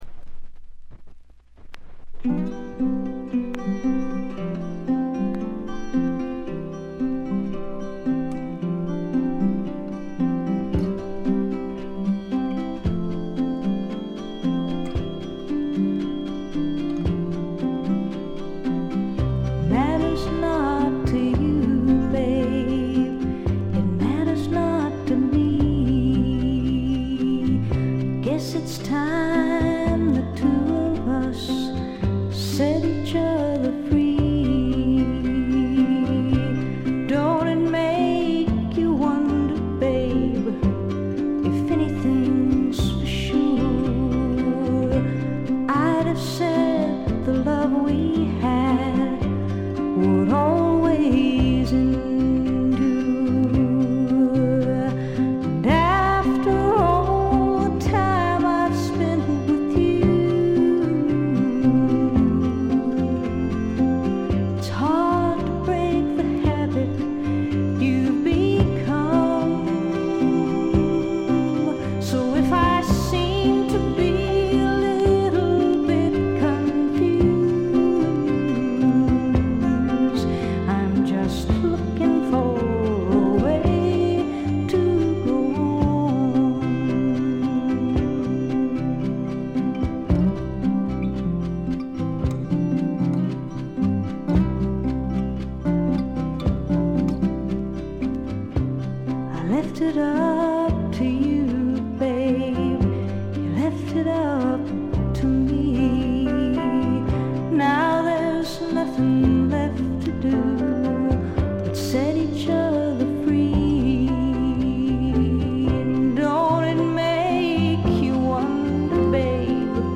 バックグラウンドノイズがほぼ常時出ており静音部でやや目立ちます。
試聴曲は現品からの取り込み音源です。
Guitar, Harmonica, Vocals